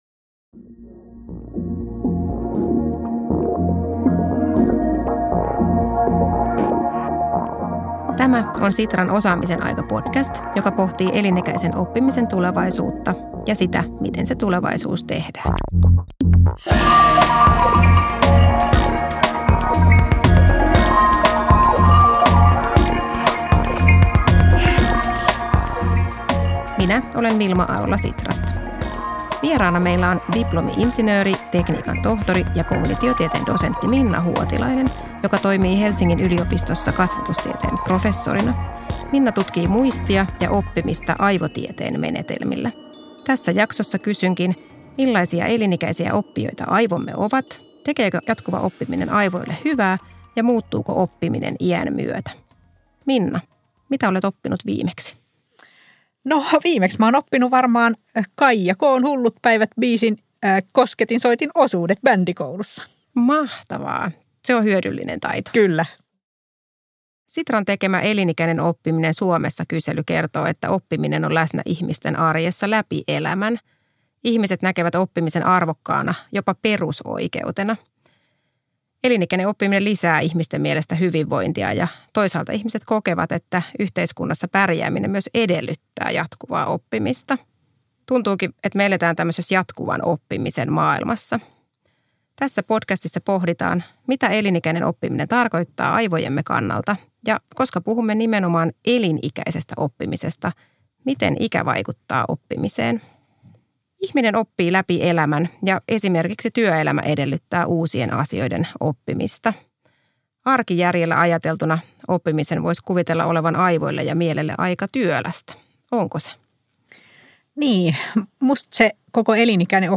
Podcast - Jatkuva oppiminen aivojen näkökulmasta (2min)